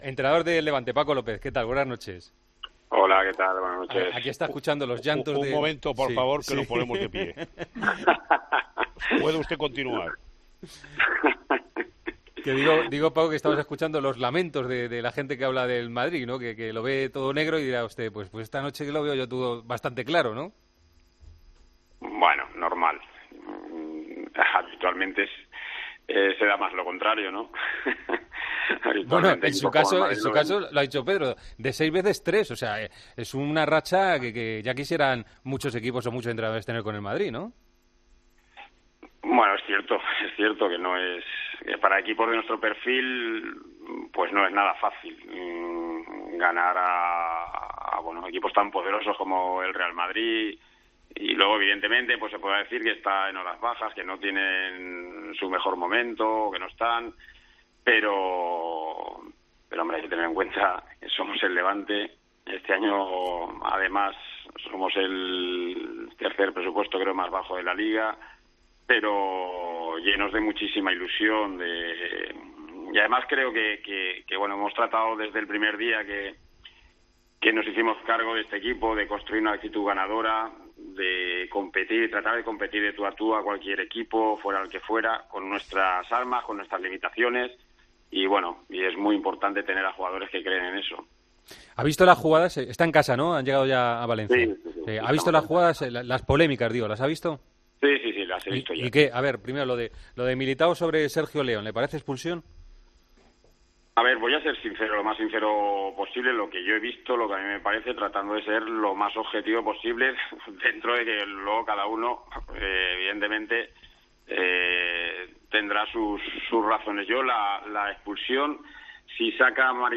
Mientras sus pupilos le daban al FIFA, el entrenador del Levante UD, Paco López, atendía la llamada del Tiempo de Juego ya desde casa y tras haber repasado el partido ante el Real Madrid.